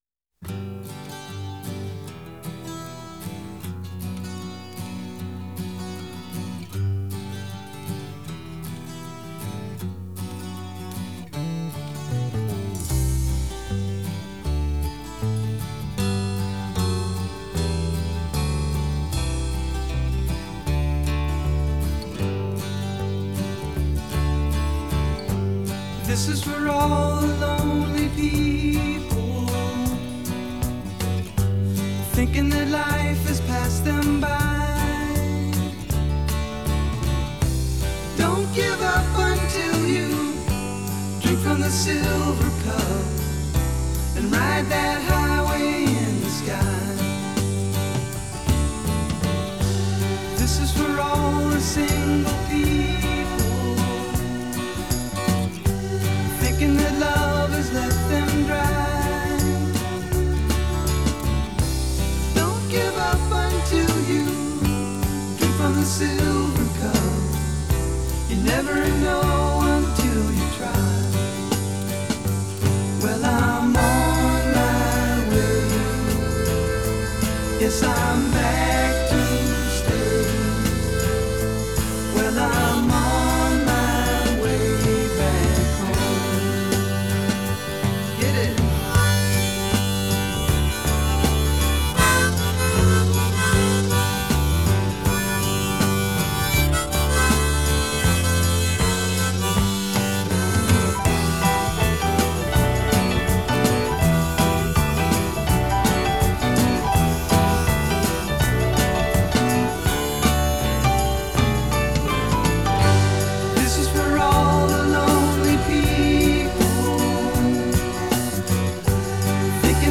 Genre: Folk-Rock.